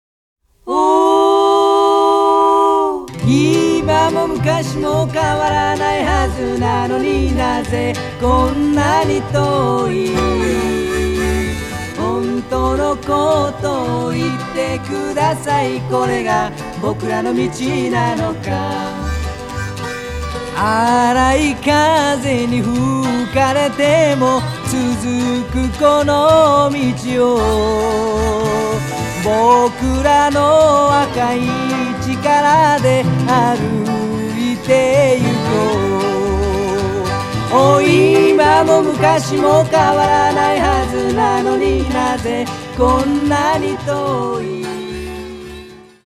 ジャンル：フォーク